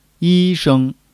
yi1--sheng1.mp3